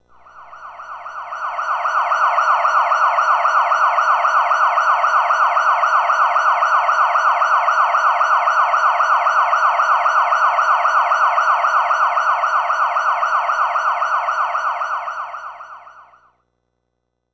Police Siren 2.ogg